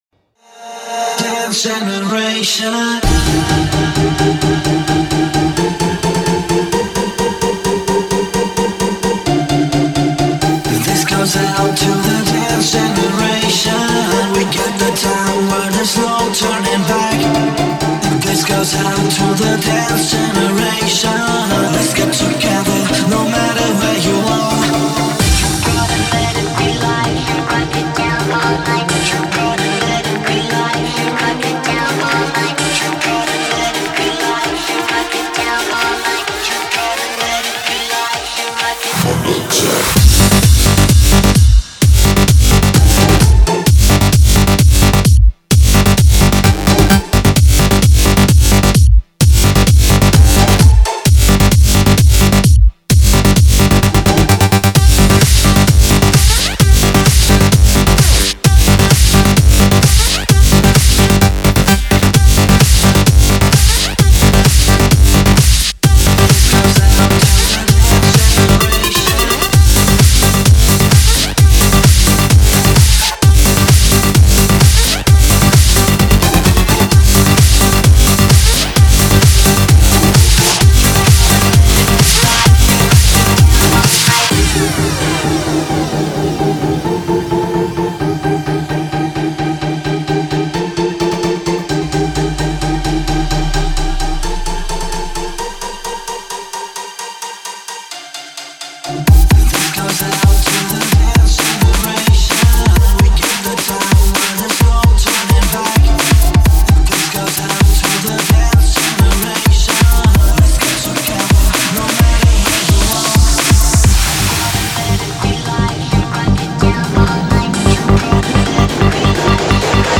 Жанр: House - Electro